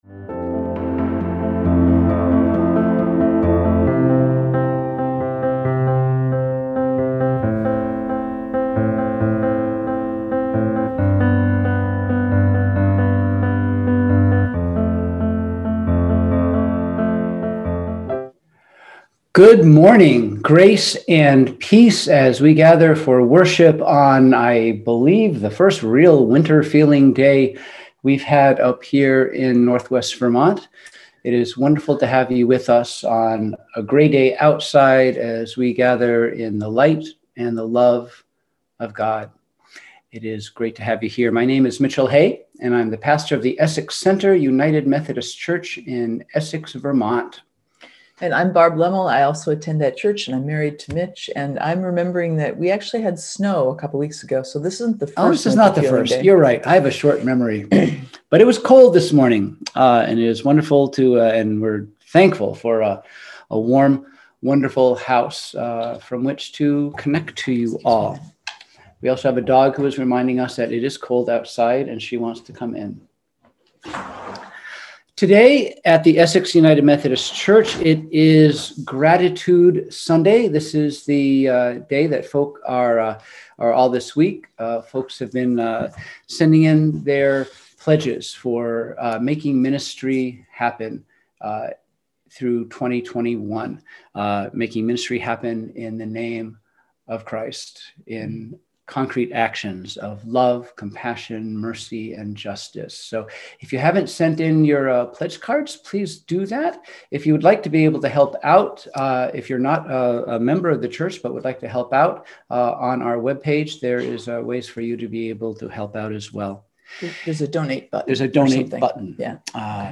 We held virtual worship on Sunday, November 15, 2020 at 10:00 am.